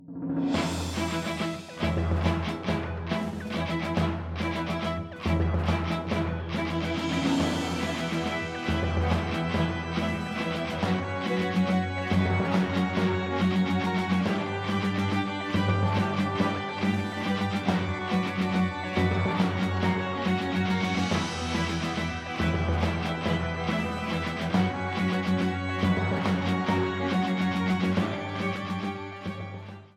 Game rip